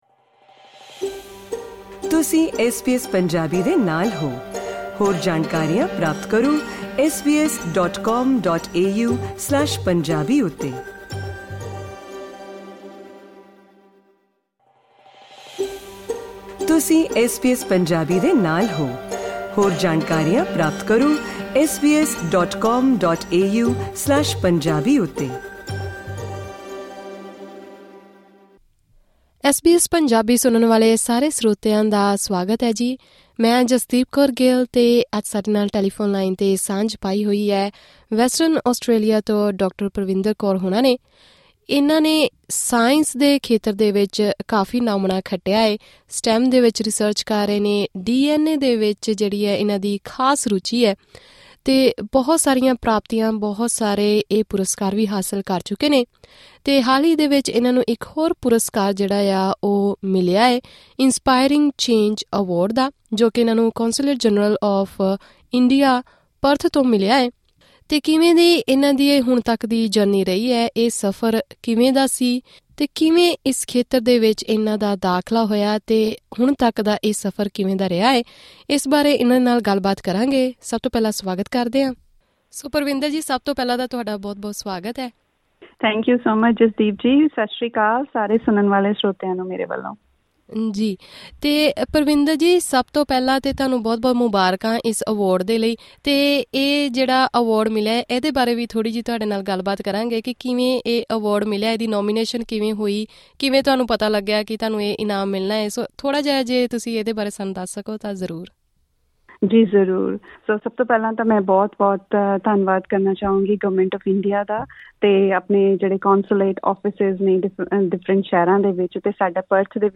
ਗੱਲਬਾਤ ਕਰਦਿਆਂ ਉਹਨਾਂ ਮਹਿਲਾਵਾਂ ਨਾਲ ਆਪਣੀ ਜ਼ਿੰਦਗੀ ਨੂੰ ਅਨੁਸ਼ਾਸਨ ਵਿੱਚ ਢਾਲਣ ਦਾ ਇੱਕ ਨੁਸਖ਼ਾ ਵੀ ਸਾਂਝਾ ਕੀਤਾ। ਪੂਰੀ ਗੱਲਬਾਤ ਸੁਣਨ ਲਈ ਇਹ ਆਡੀਓ ਇੰਟਰਵਿਊ ਸੁਣੋ..